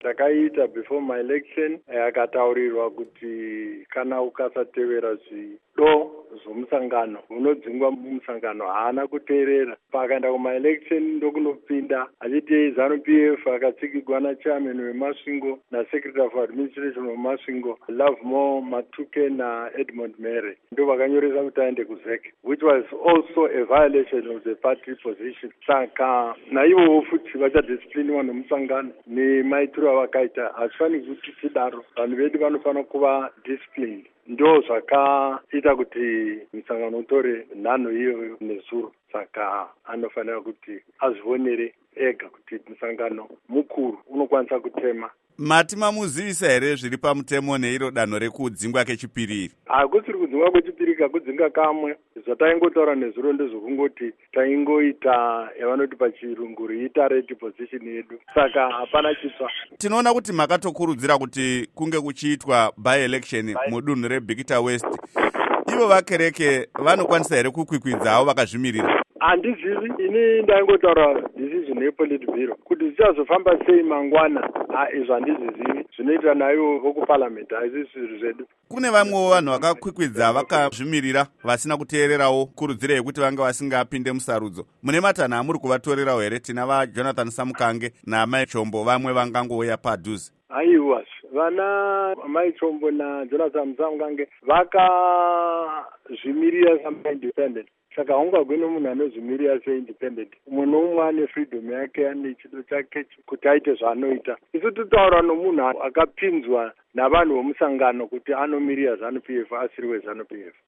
Hurukuro naVaRugare Gumbo